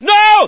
lots of screaming scientists
scream10.ogg